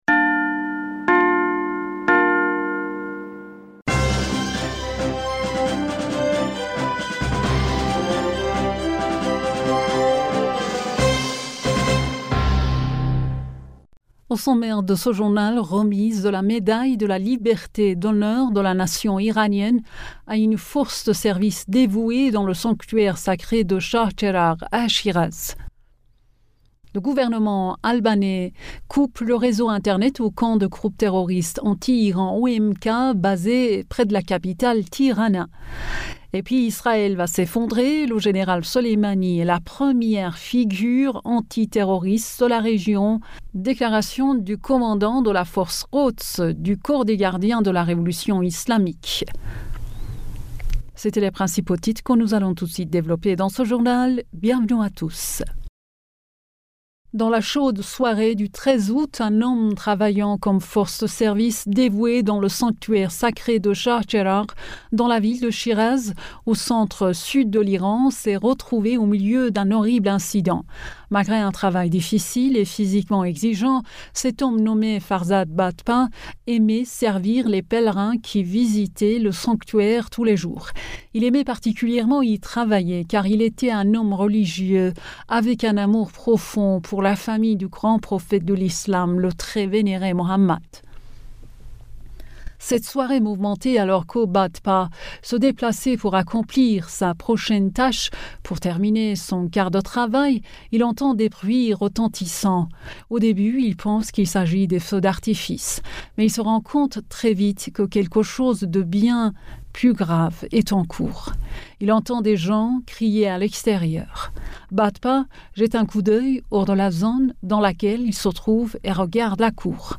Bulletin d'information du 20 Aout 2023